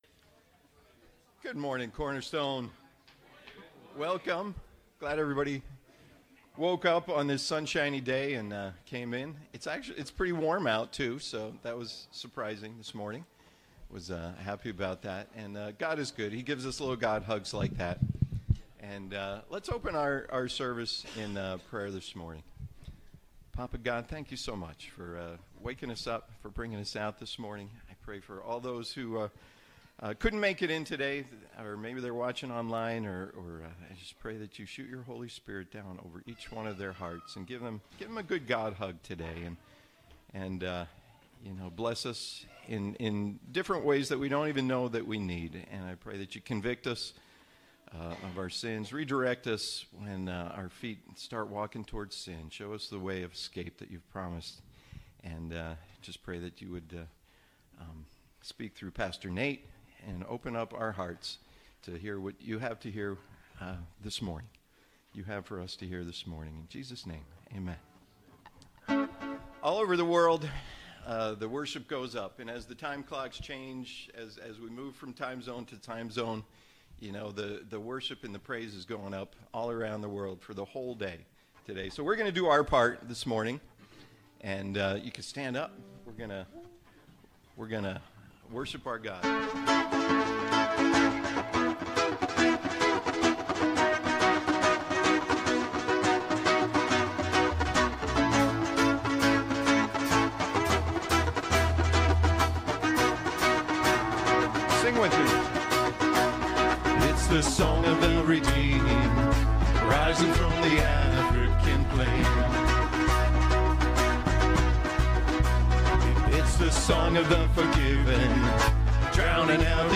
The Promise Passage: Genesis 19 Service Type: Sunday Morning Youversion Event « Is there a limit to God’s grace Is Fear a GOOD thing or a BAD thing?